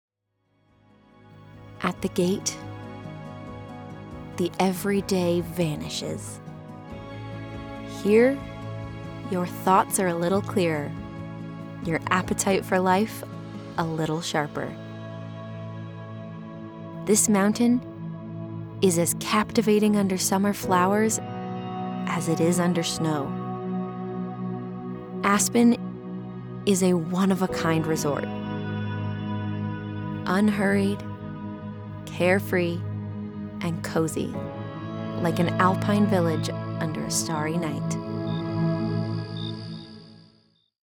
Aspen Alpine Village - Documentary Demo
I do voiceover full time from a professionally treated in-home studio using a Sennheiser MKH 416 microphone and Apollo Twin X Duo interface.
I was born just outside Seattle Washington, which means I offer that neutral accent that can be used nation wide.
Friendly, conversational, girl next door